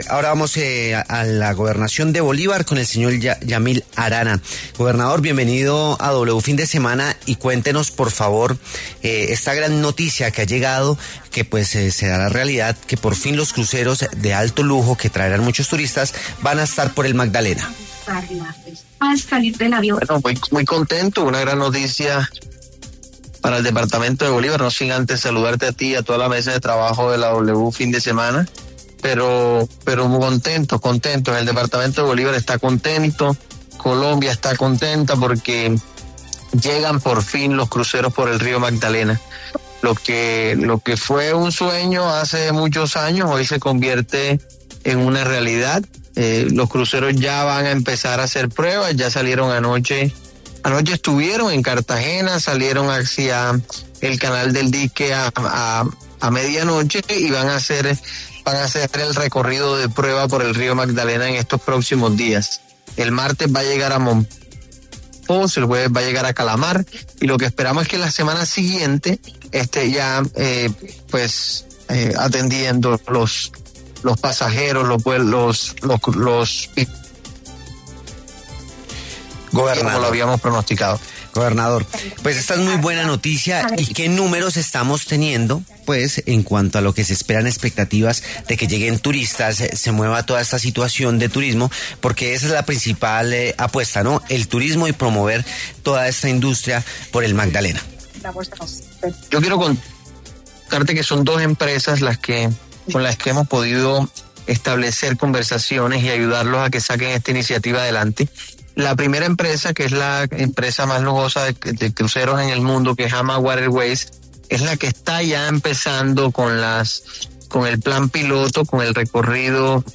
Yamil Arana, gobernador de Bolívar, conversó en los micrófonos de W Fin de Semana sobre la llegada de los primeros cruceros de lujo con cientos de turistas que pasarán por el Magdalena.